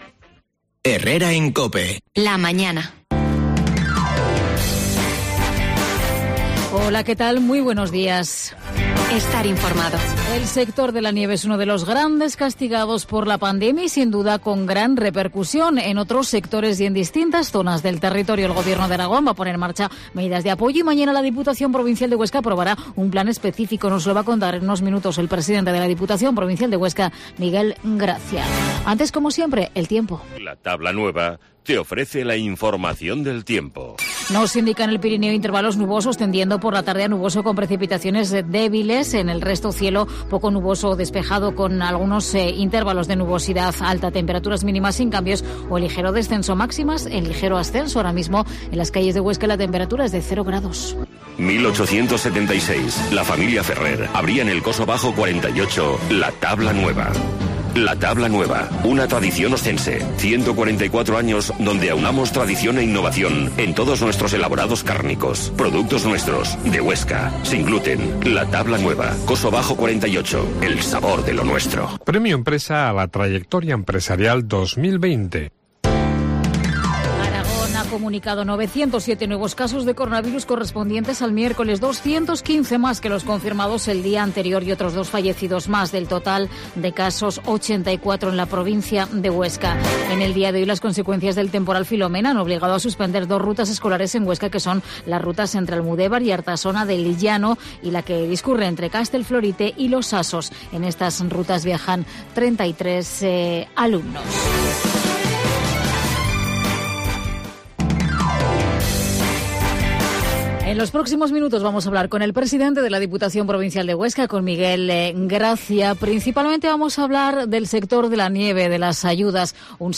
Herrera en COPE Huesca 12.50h Entrevista al Presidente de la DPH, Miguel Gracia